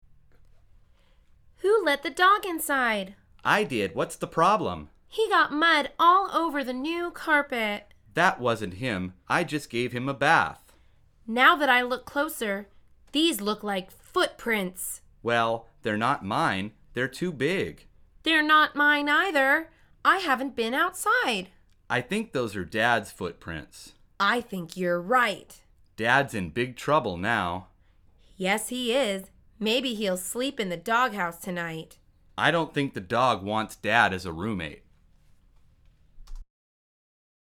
مجموعه مکالمات ساده و آسان انگلیسی – درس شماره نهم از فصل مسکن: حیوانات خانگی تو خونه